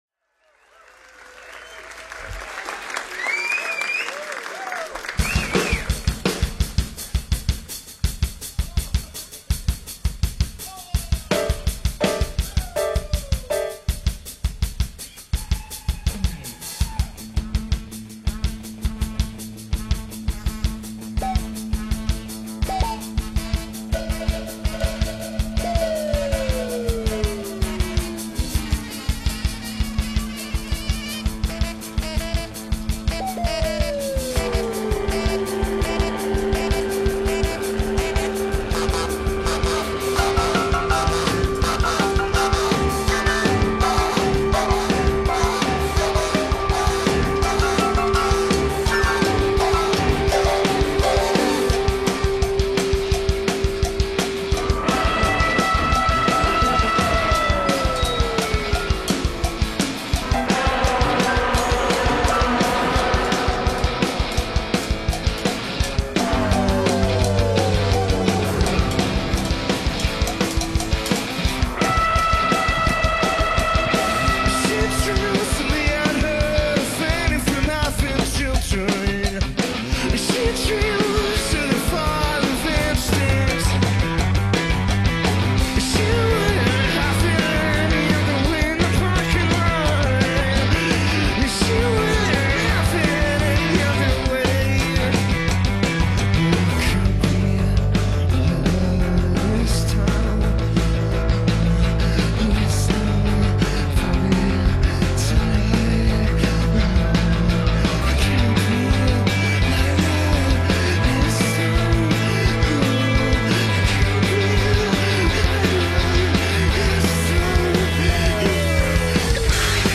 Dutch Indie conglomerate